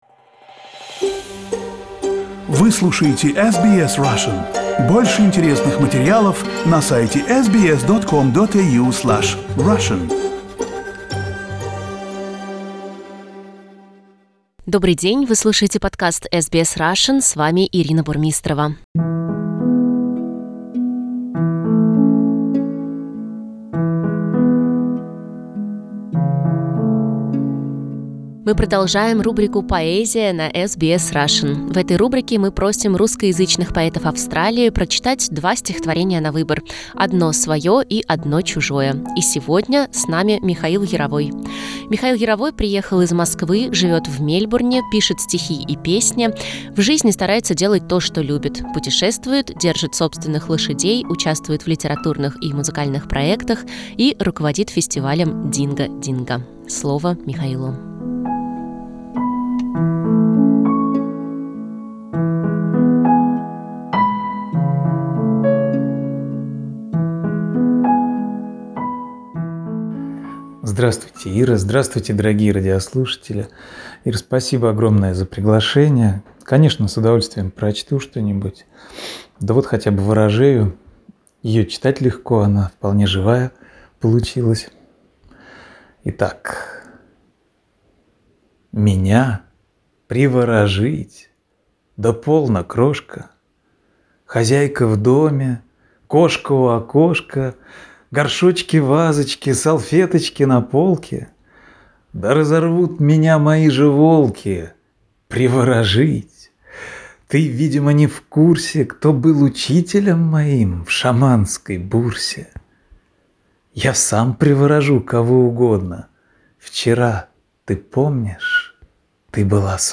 Поэзия.
В этой рубрике мы просим австралийских поэтов прочесть два стихотворения: одно свое и одно чужое.